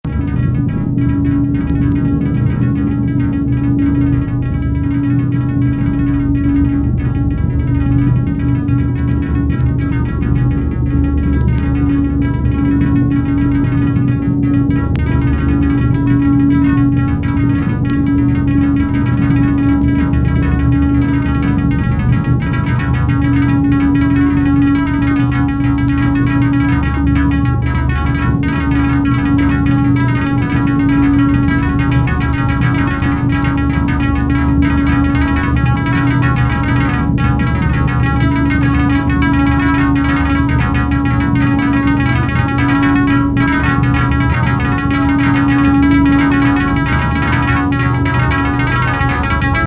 冒頭のインダストリアルなドローン音から大興奮!
ガムランのようなシロフォンの演奏と電子音が交錯するトランス・アシッド・ナンバー